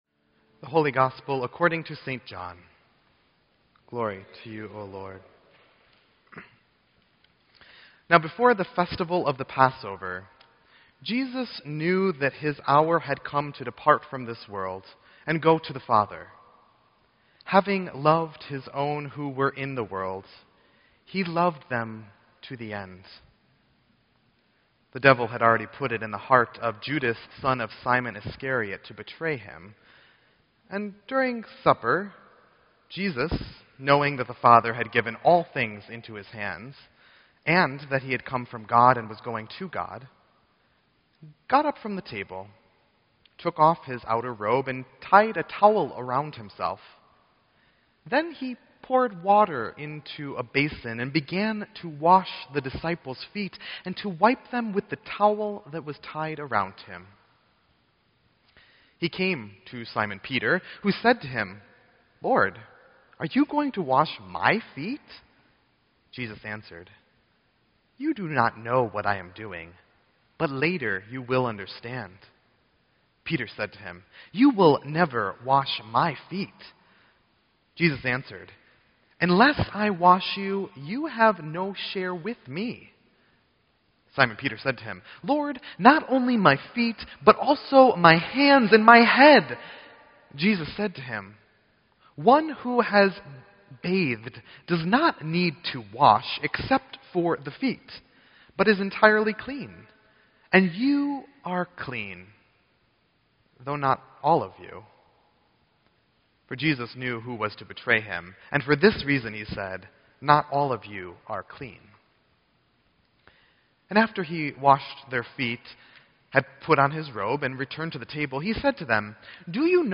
Sermon_3_24_16-1.mp3